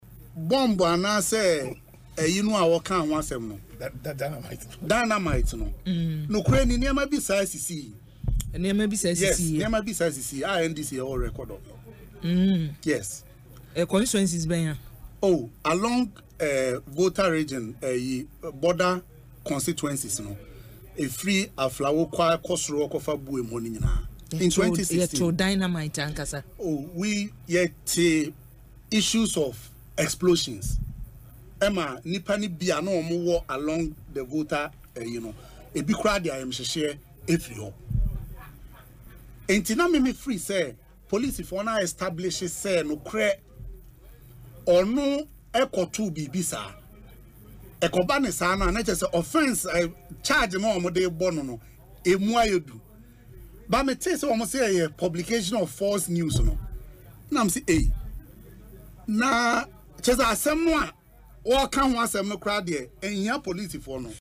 He disclosed this on Asempa FM’s Ekosii Sen programme Friday but said he cannot  confirm the type of explosion it was.